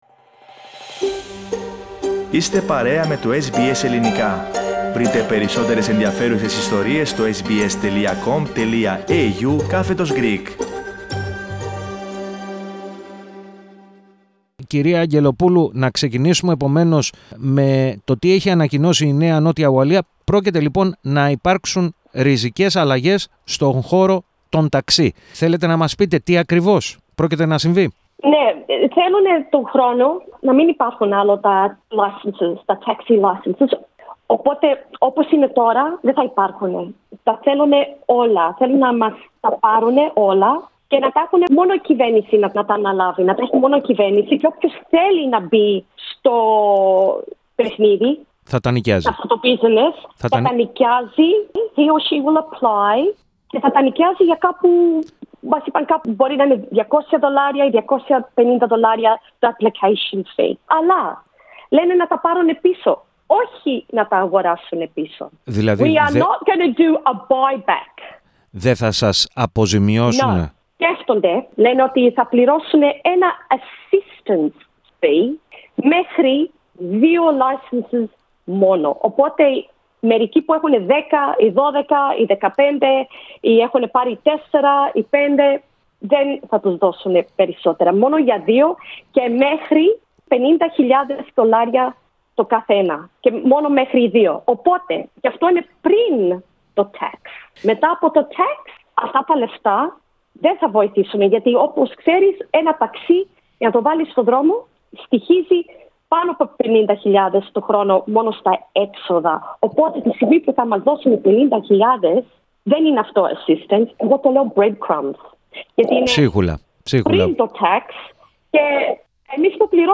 Ακούστε ολόκληρη τη συνέντευξη πατώντας το σύμβολο στην κεντρική φωτογραφία READ MORE Να επενδύσω σε ακίνητο; Προβλέψεις του Ελληνοαυστραλού "βασιλιά" στο real estate READ MORE Νέο φάρμακο με ελληνική υπογραφή κατά του κορωνοϊού Share